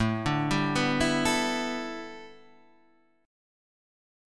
A9sus4 chord {5 5 5 7 5 7} chord